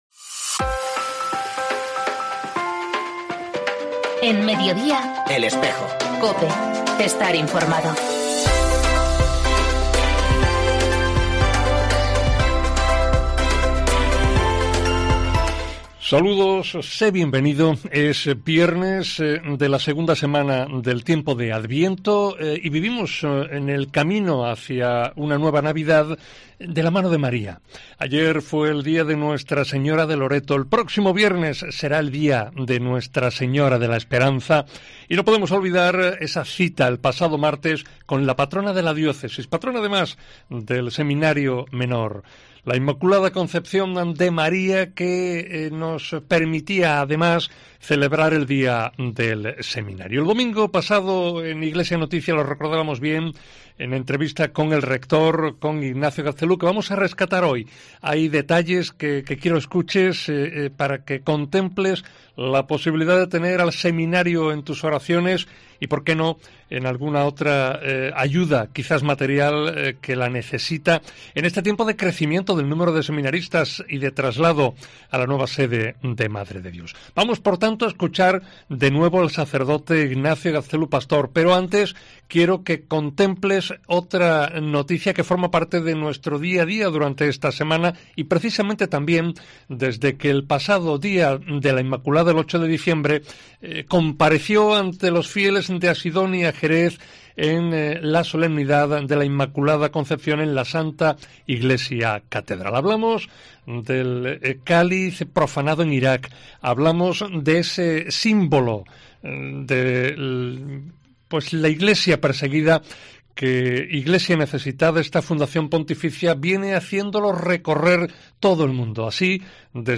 una entrevista